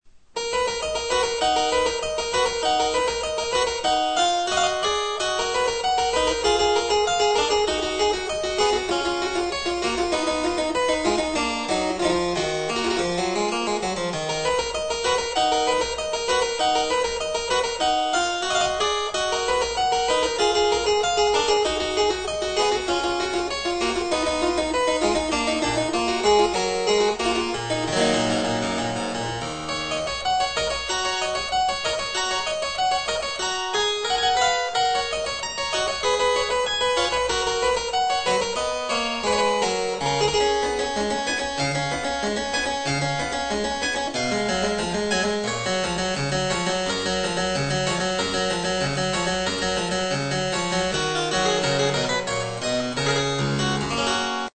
CLAVECIN ALLEMAND
Il a deux claviers avec des marches en ébène et des feintes en fruitier noirci plaquées en ivoire.
J'ai rajouté un jeu de luth.